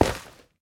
Minecraft Version Minecraft Version 25w18a Latest Release | Latest Snapshot 25w18a / assets / minecraft / sounds / block / nylium / step6.ogg Compare With Compare With Latest Release | Latest Snapshot
step6.ogg